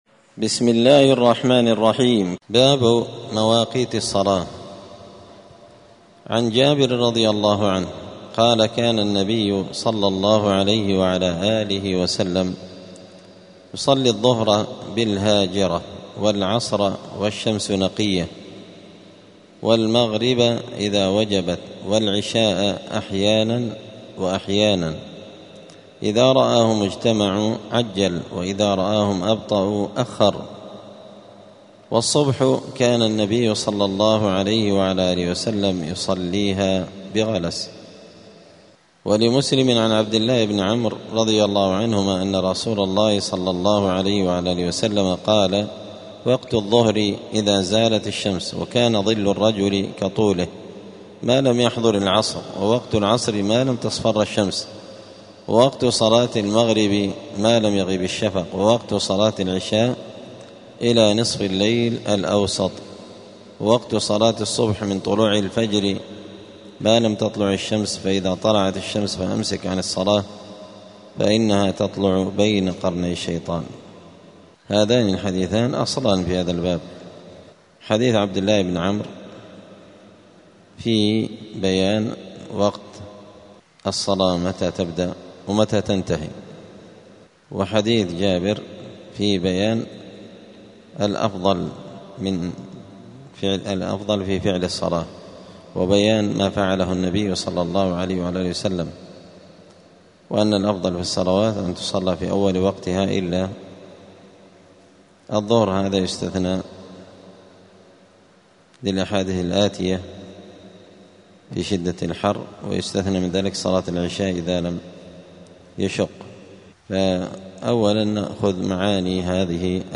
دار الحديث السلفية بمسجد الفرقان قشن المهرة اليمن
*الدرس الخامس والعشرون بعد المائة [125] {باب مواقيت الصلاة}*